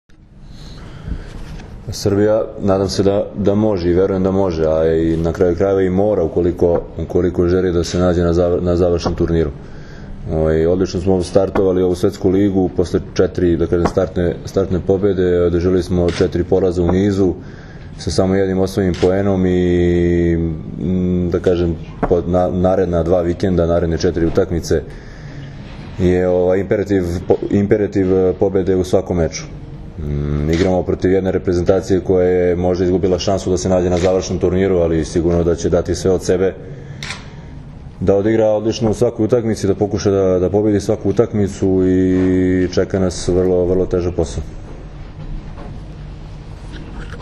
Tim povodom, danas je hotelu “Sole mio” u Novom sadu održana konferencija za novinare, kojoj su prisustvovali Dragan Stanković, Todor Aleksijev, Igor Kolaković i Kamilo Plaći, kapiteni i treneri Srbije i Bugarske.
IZJAVA DRAGANA STANKOVIĆA